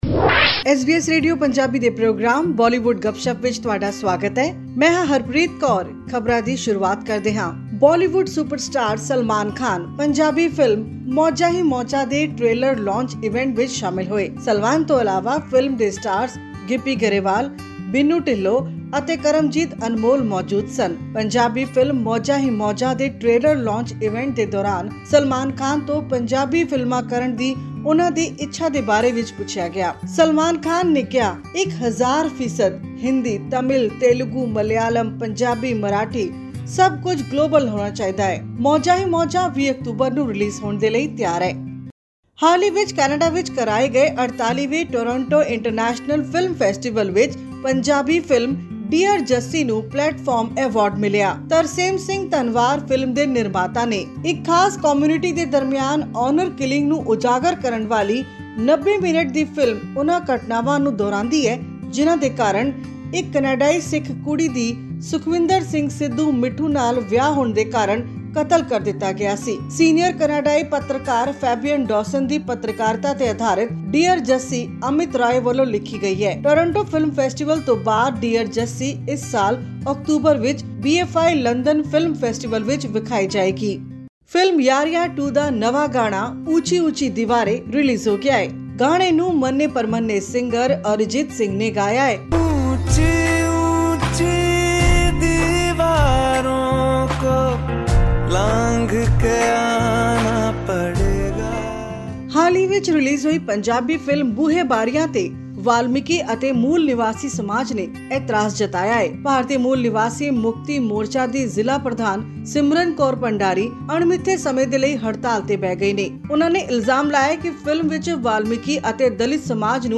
This and more in our weekly news segment of Bollywood Gupshup on upcoming movies and songs.